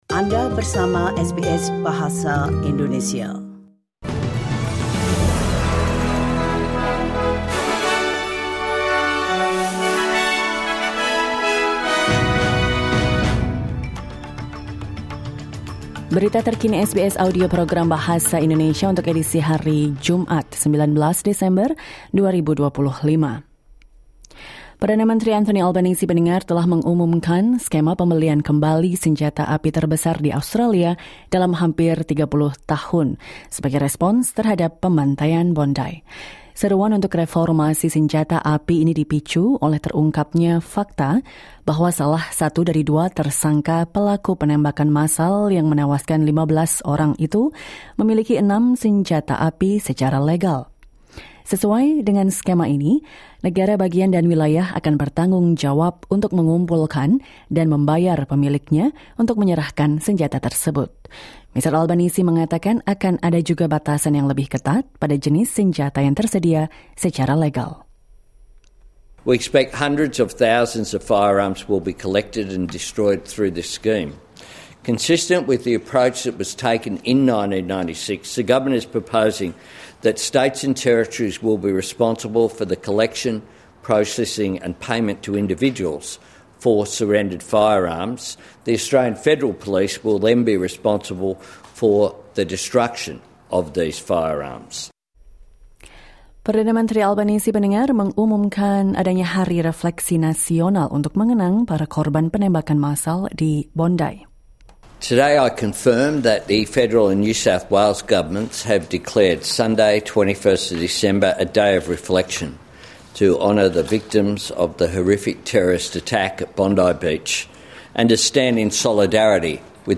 Latest News SBS Audio Indonesian Program - Friday 19 December 2025